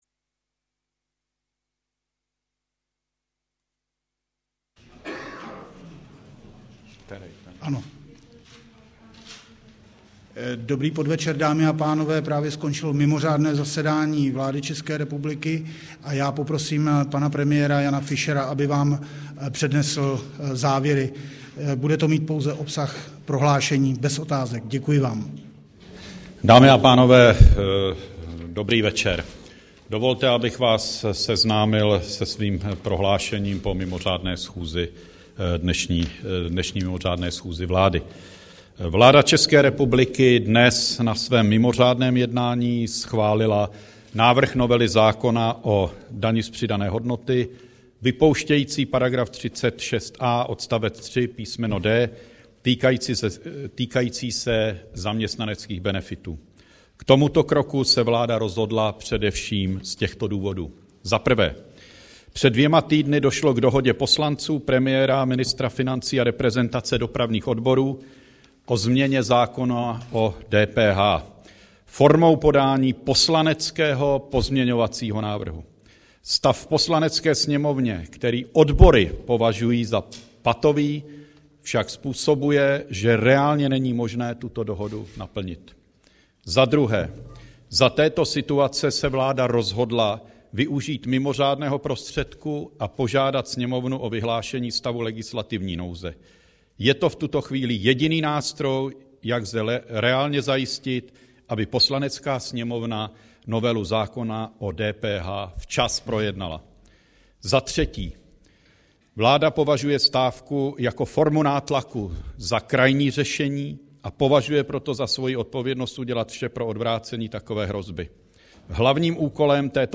Tiskový brífink premiéra po mimořádném jednání vlády, 25. února 2010
Tiskové prohlášení premiéra po mimořádném jednání vlády, 25. února 2010